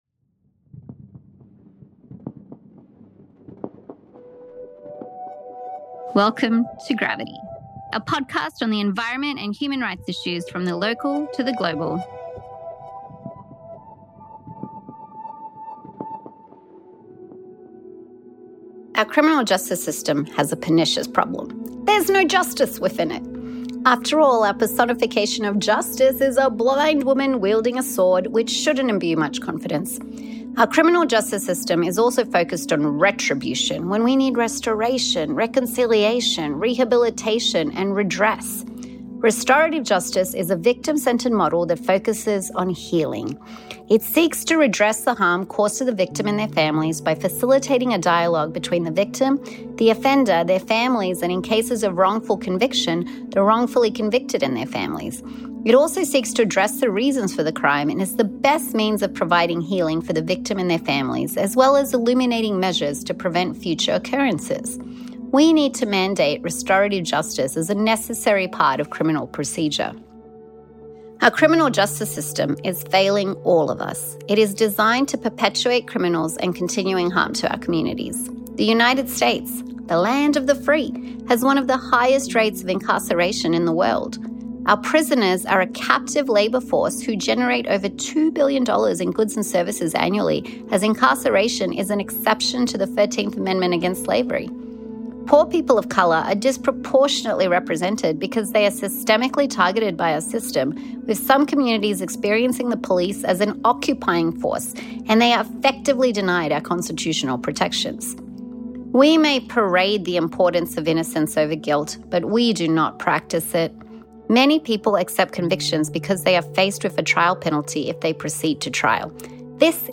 Discussing human rights and environmental issues from the legal, political and ethical fronts with interviews from activists, NGOs, authors and professors.